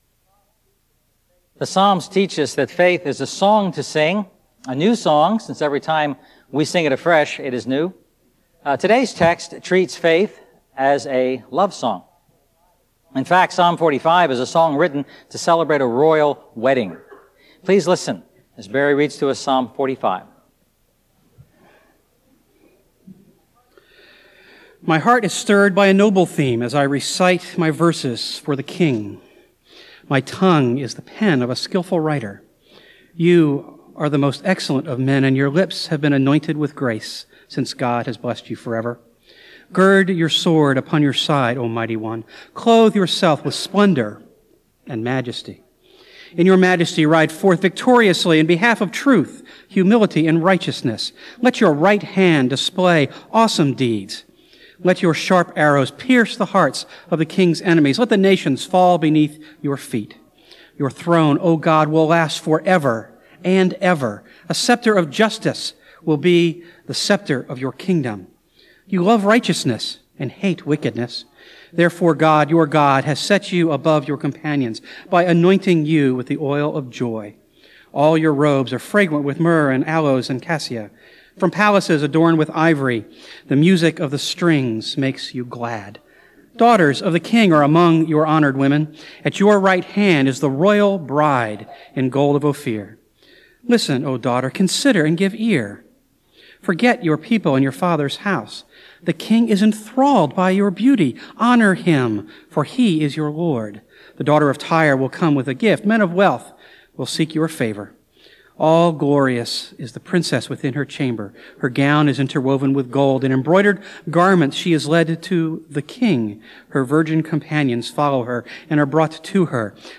A message from the series "A New Song."